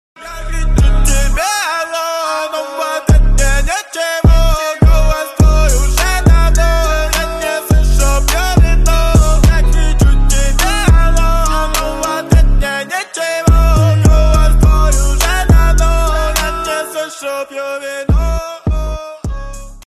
• Качество: 320 kbps, Stereo
Рэп и Хип Хоп
грустные